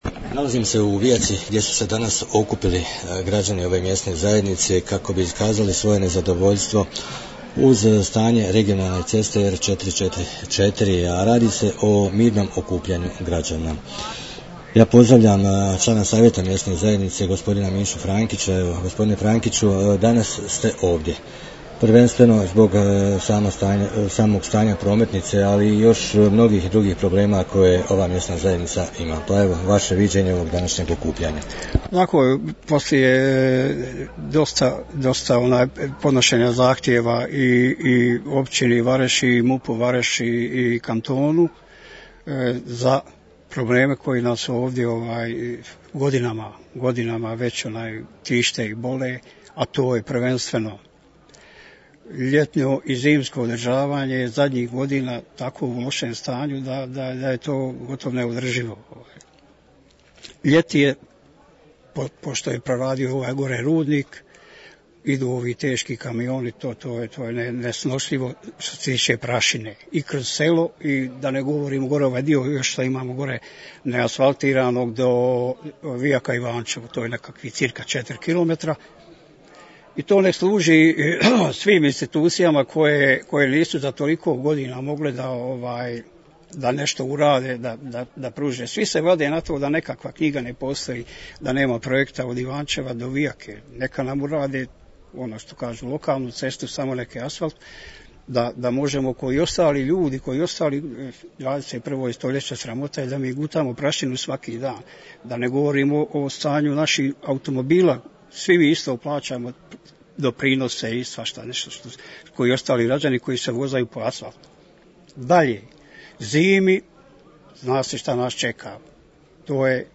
Danas, 07.08.2025. godine okupili su se mještani kod Doma u Vijaci kako bi iskazali svoje nezadovoljstvo stanjem ove putne komunikacije. Teretna vozila stvaraju ogromnu prašinu u ljetnim mjesecima, a u zimskim stanje je još alarmantnije zbog velikog snijega i rupa koje nastaju u kišnom razdoblju.